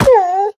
Minecraft Version Minecraft Version latest Latest Release | Latest Snapshot latest / assets / minecraft / sounds / mob / wolf / cute / hurt2.ogg Compare With Compare With Latest Release | Latest Snapshot
hurt2.ogg